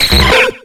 Cri de Métamorph dans Pokémon X et Y.